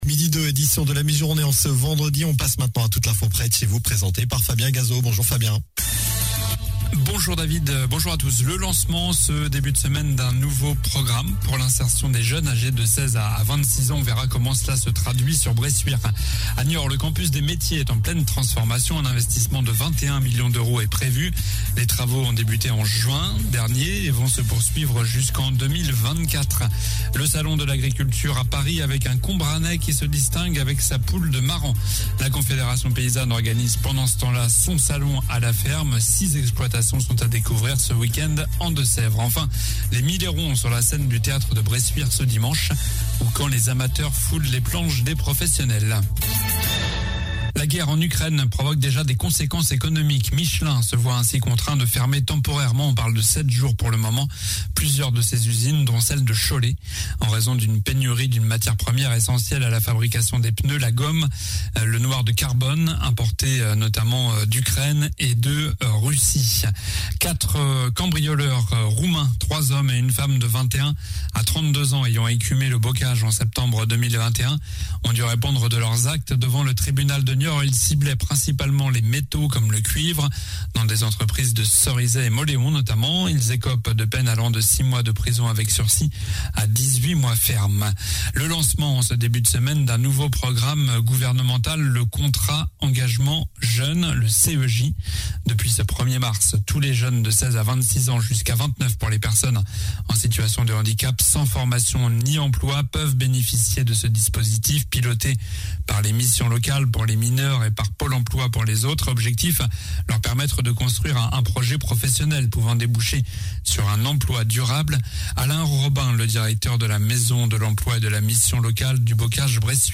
Journal du vendredi 04 février (midi)